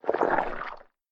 snd_slimedie.ogg